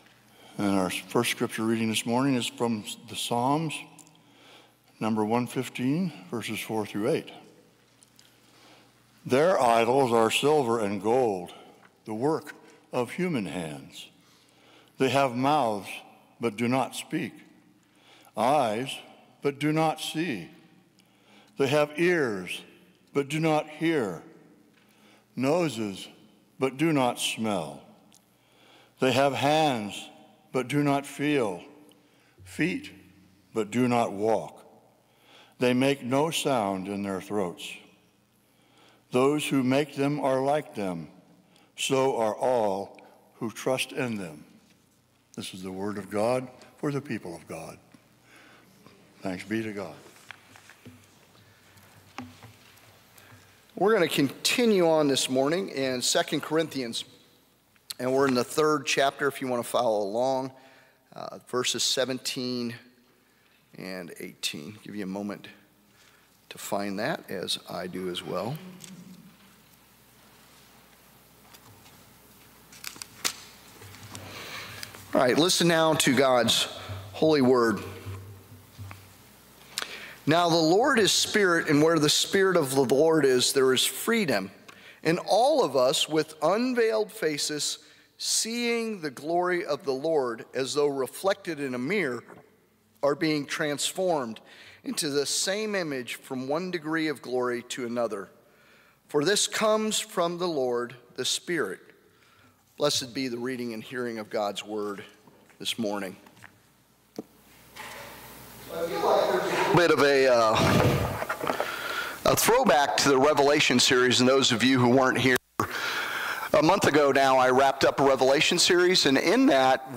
August-3-2025-sermon-audio-only.m4a